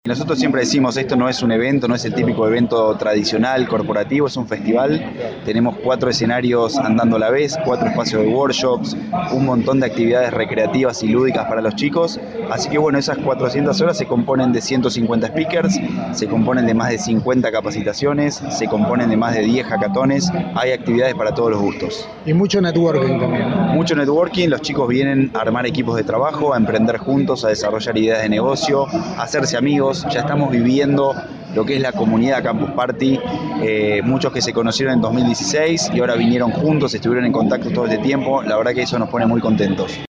Ciclo 2018, Entrevistas
SobreCiencia está presente en Campus Party 2018 que se realiza hasta el 28 de abril en Tecnópolis, Argentina